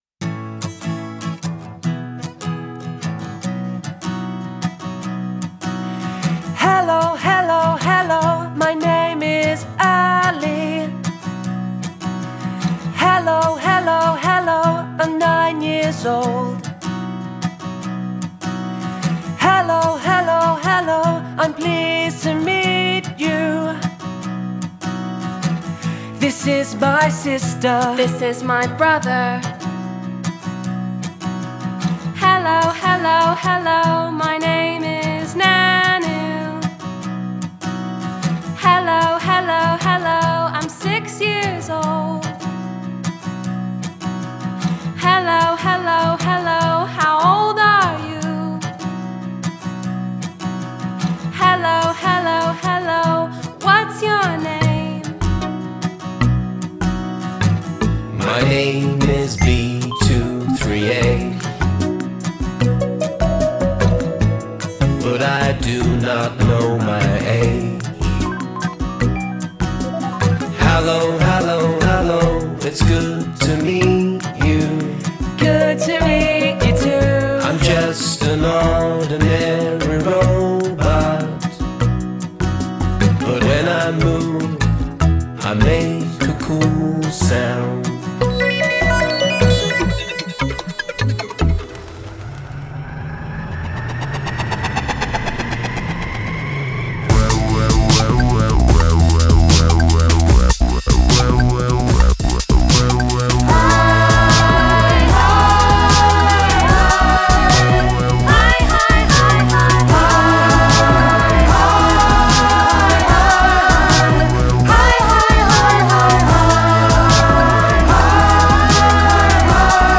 Robot songs